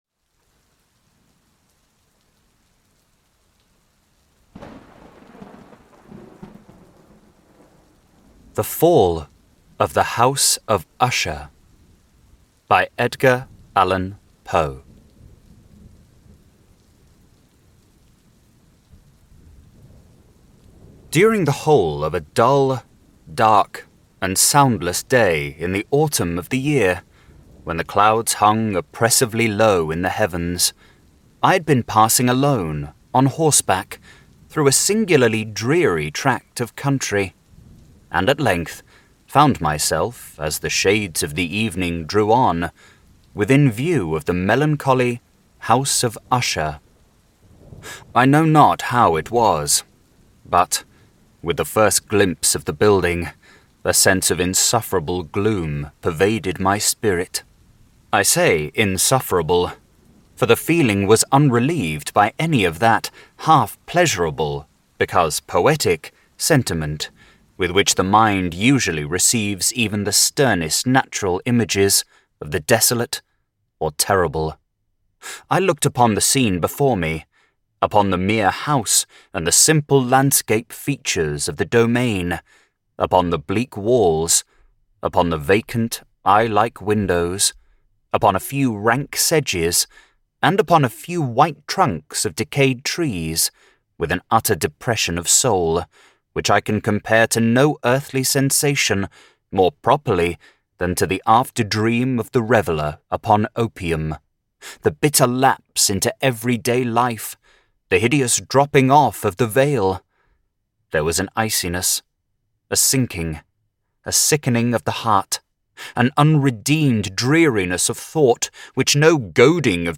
The Curious Case of Benjamin Button by F. Scott Fitzgerald - Timeless Audiobook